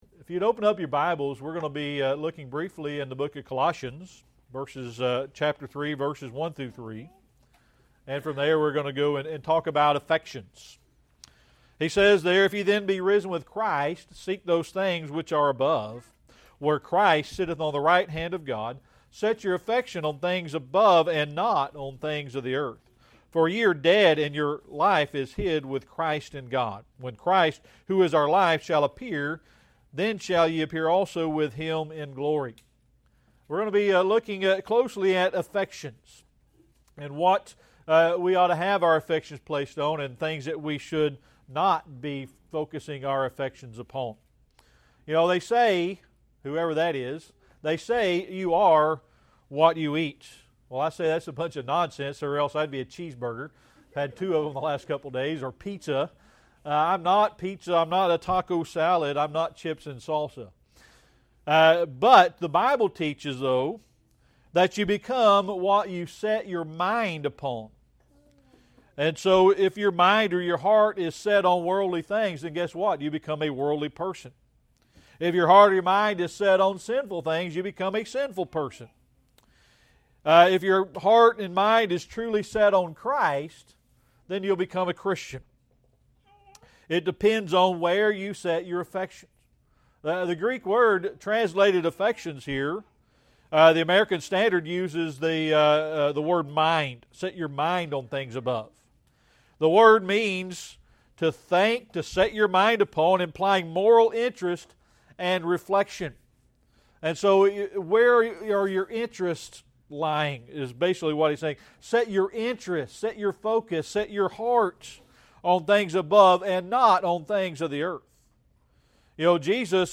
Series: Sermon Archives
Service Type: Sunday Morning Worship